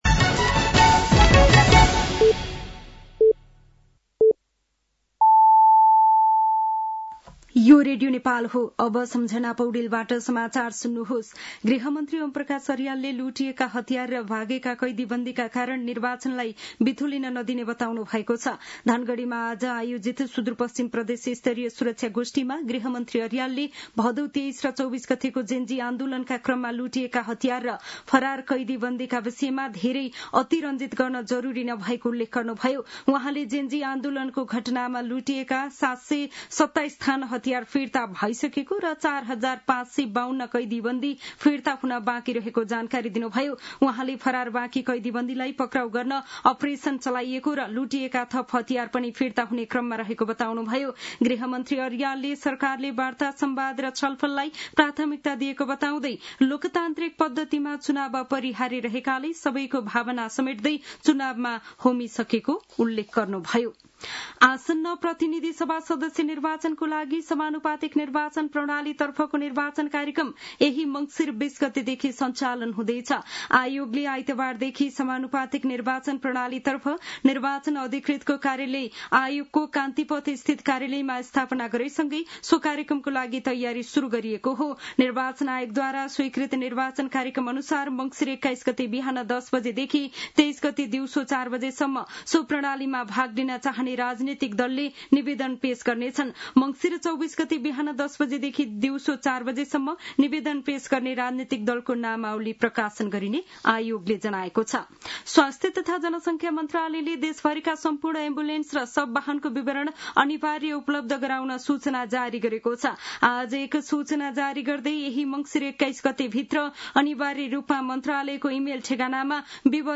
साँझ ५ बजेको नेपाली समाचार : १६ मंसिर , २०८२